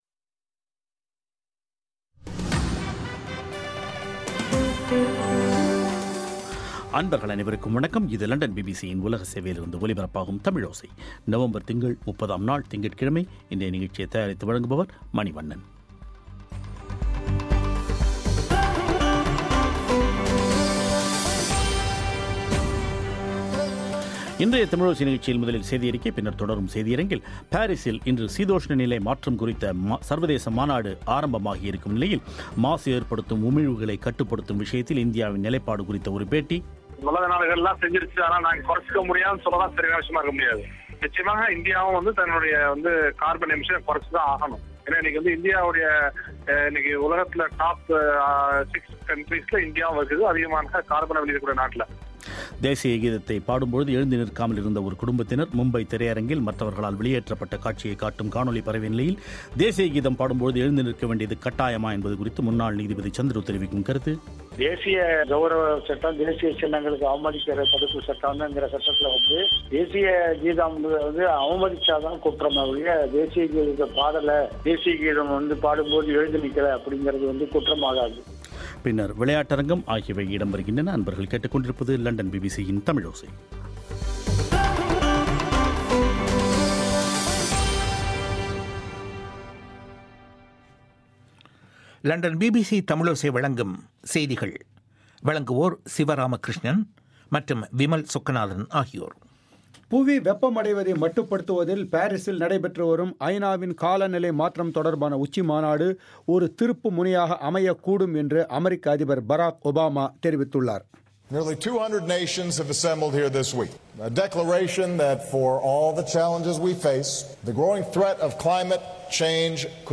பாரிசில் காலநிலை மாற்றம் தொடர்பான சர்வதேச மாநாடு ஆரம்பமாகியிருக்கும் நிலையில் கரியமில வாயுவின் வெளியேற்றத்தில் இந்தியாவின் நிலைப்பாடு குறித்த ஒரு பேட்டி.